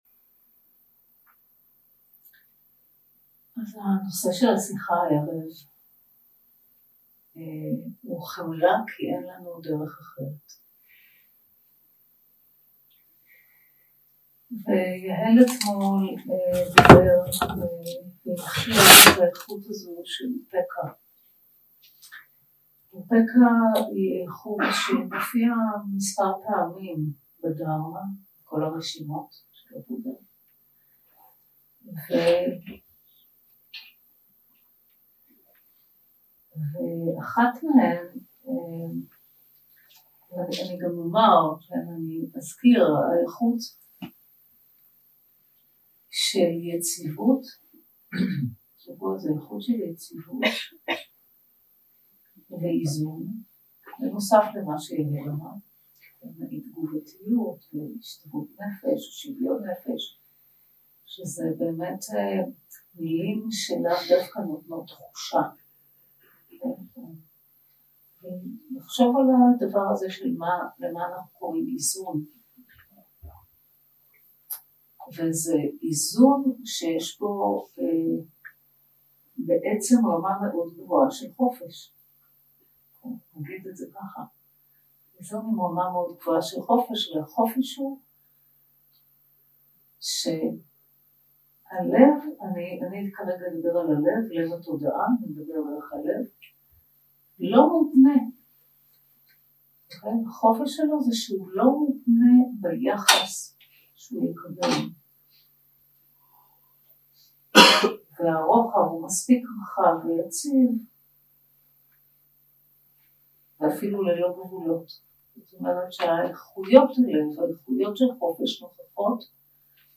יום 3 – הקלטה 7 – ערב – שיחת דהארמה – חמלה - כי אין לנו דרך אחרת 0:00 0:00 סוג ההקלטה: Dharma type: Dharma Talks שפת ההקלטה: Dharma talk language: Hebrew